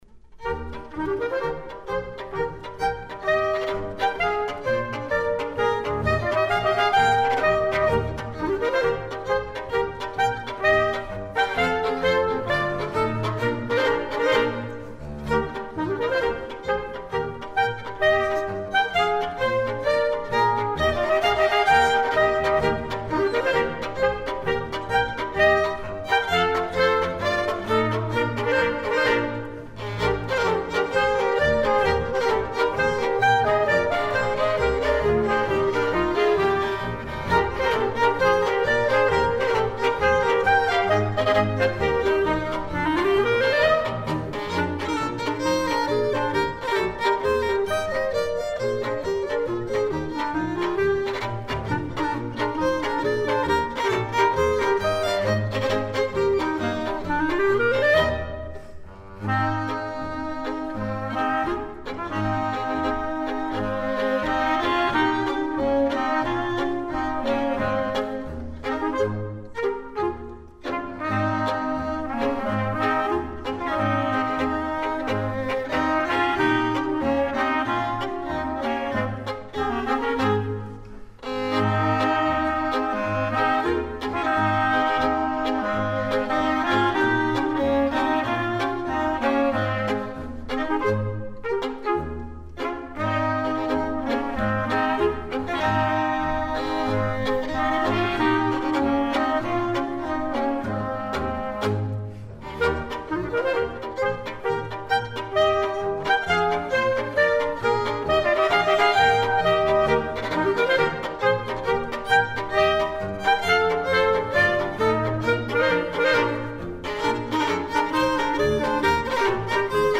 Traditional dances from Canton Grisons.
cornet
clarinet
violin
viola
bass